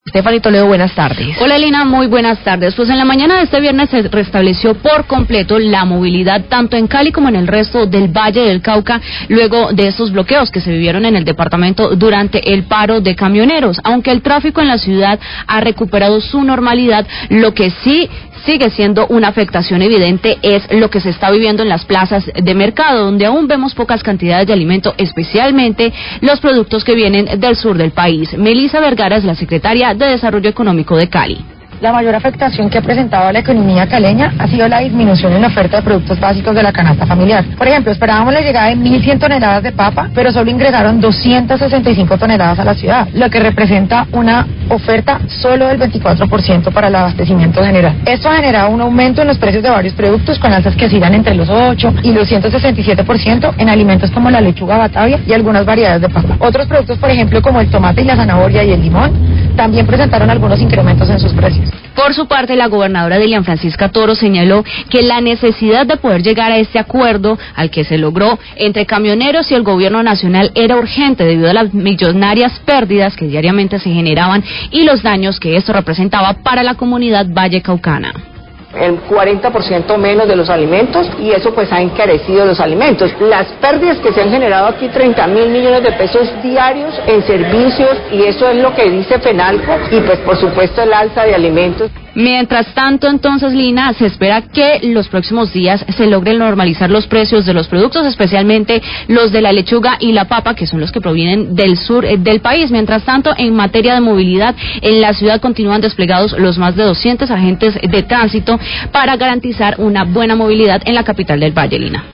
Radio
La Secretaria de Desarrollo Económico de Cali, Melisa Vergara, se refiere a las afectaciones por el paro camionero que deja un desabastecimiento de varios alimentos y el consecuente aumento de precios.